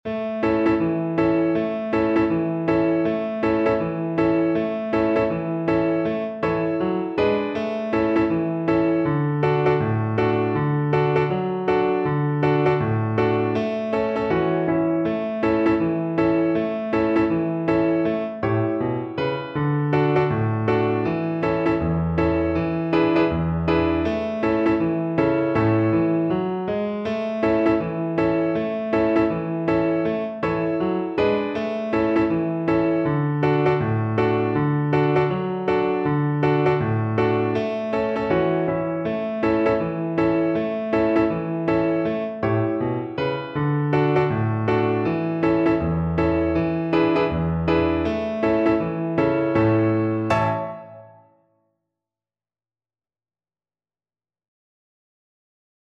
2/2 (View more 2/2 Music)
Fast Swing =c.80